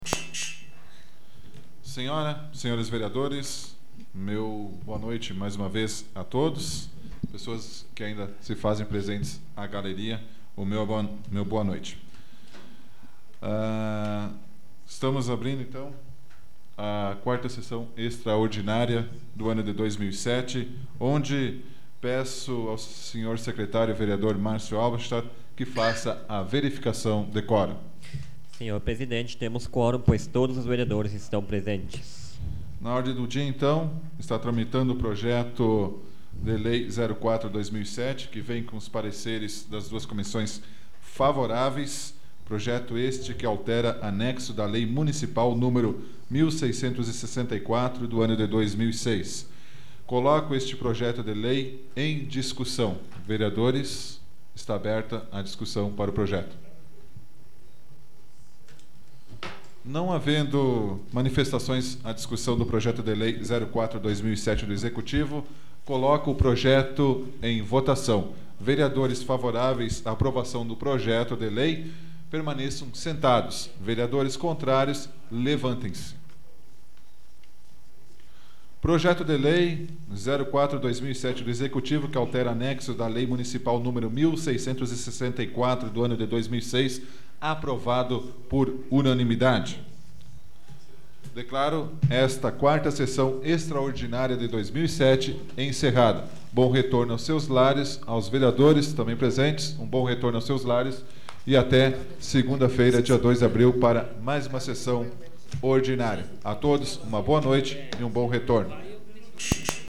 Áudio da 34ª Sessão Plenária Extraordinária da 12ª Legislatura, de 26 de março de 2007